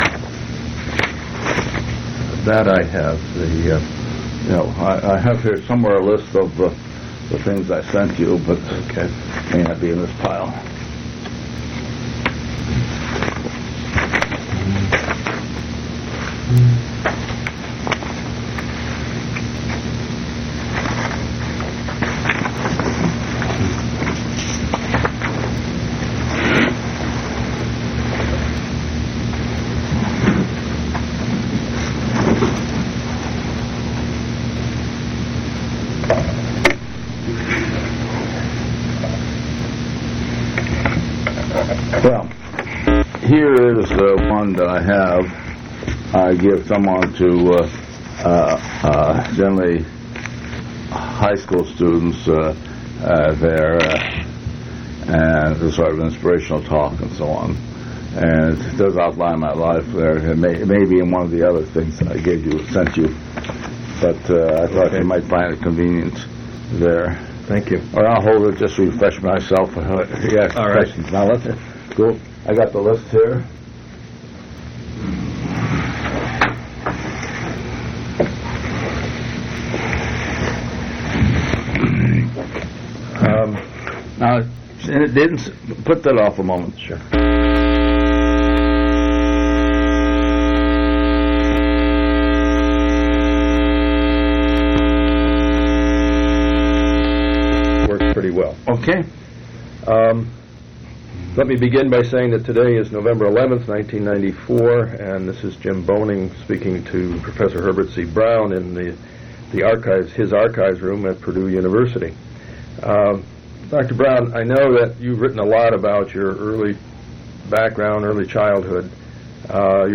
Oral history interview with Herbert C. Brown